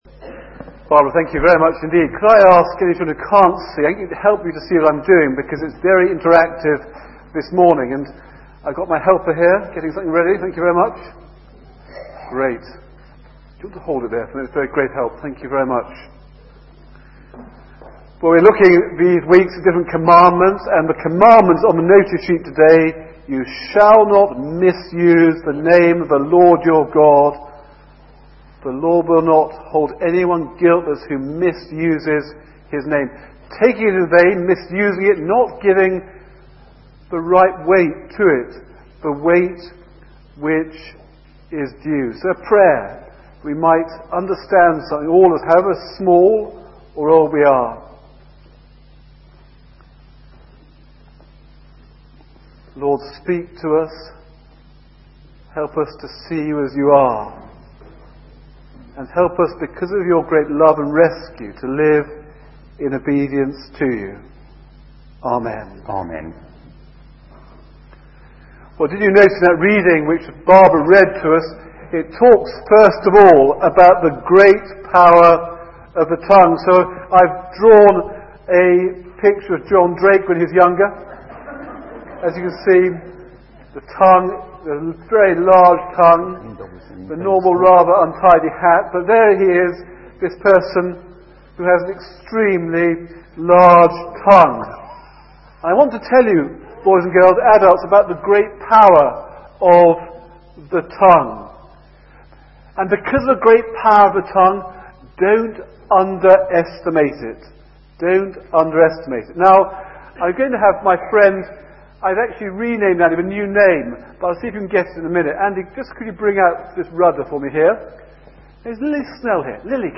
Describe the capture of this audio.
Most sermons at St. Mary's are recorded and are available as computer files (.mp3) so that you can listen to them on your computer at home, or download them to transfer them to your portable music player (eg iPod).